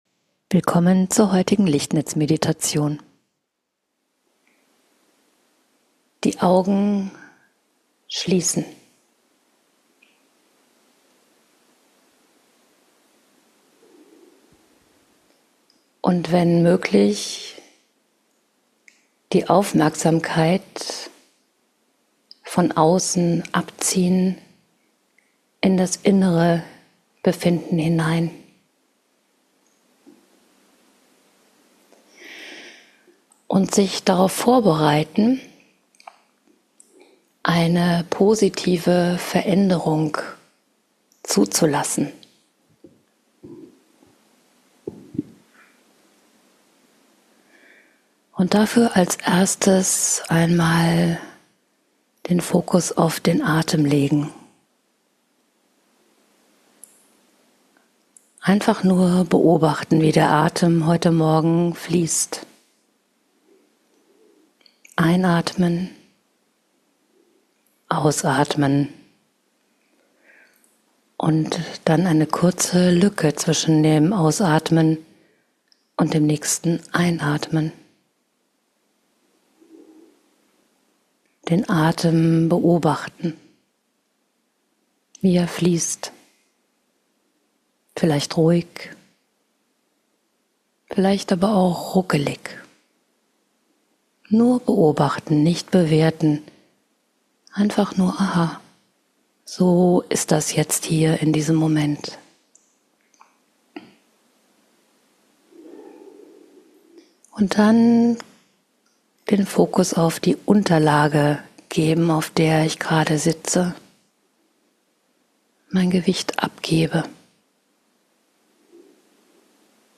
In dieser Meditation erfährst und erspürst du, wie man sich ganz leicht in die Frequenz der Liebe hinein begibt, darin entspannt, badet, und sich mit diesem wunderbaren Gefühl für den Alltag auftankt.